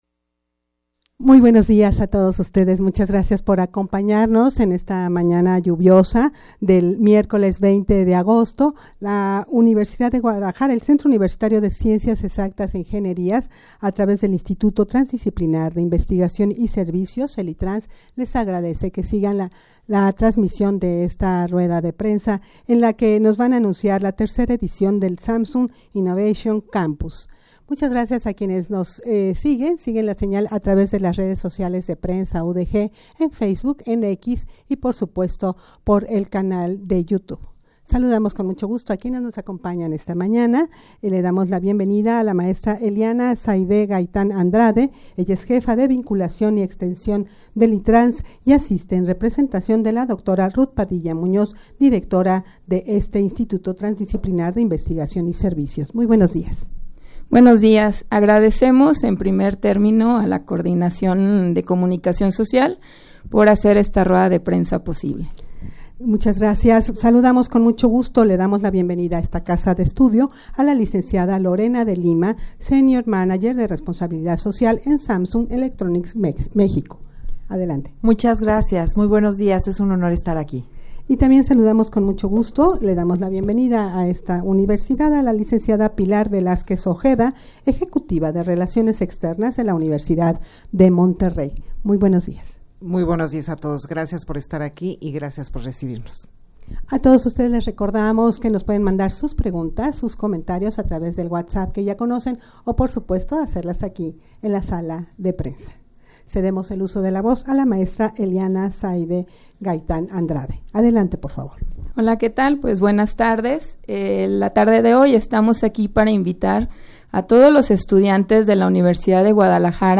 Audio de la Rueda de Prensa
rueda-de-prensa-para-anunciar-la-tercera-edicion-del-samsung-innovation-campus.mp3